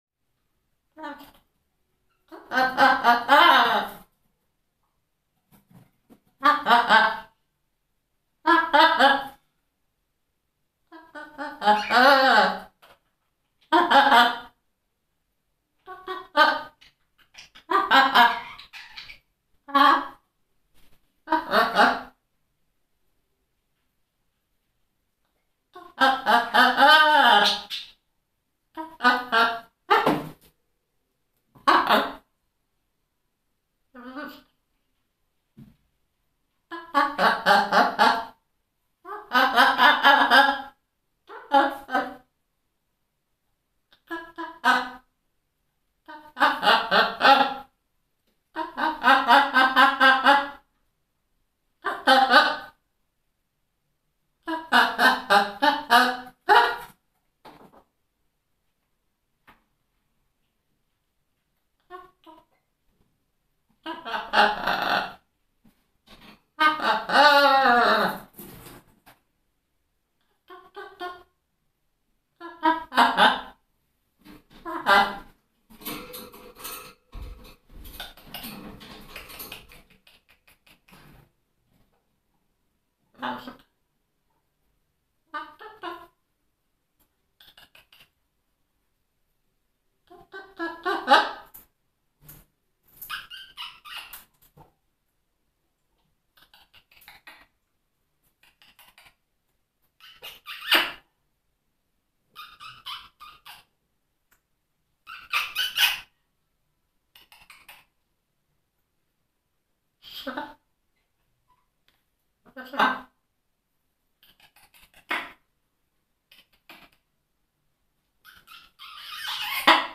popugaj-ara-smeyotsya-i-izobrazhaet-zvuk-poczeluya.mp3